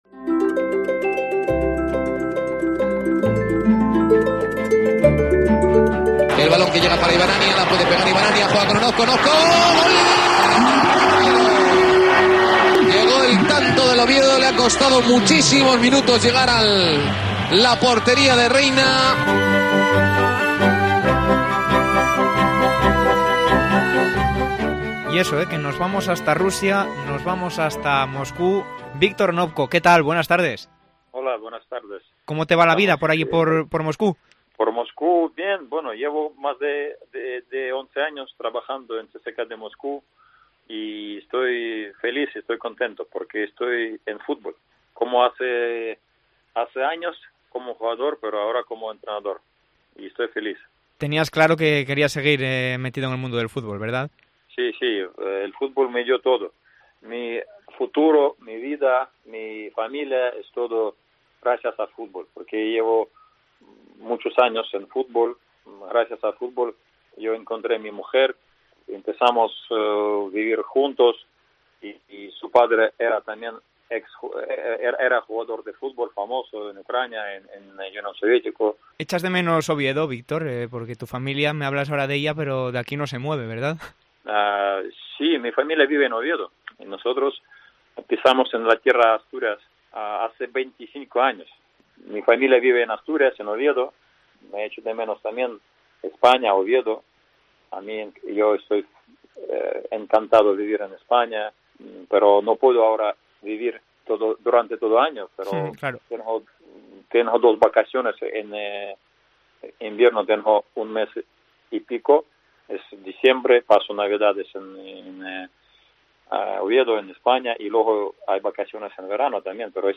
Desde Rusia, inmerso en plena temporada con el CSKA de Moscú, Viktor Onopko atiende la llamada de Deportes COPE Asturias.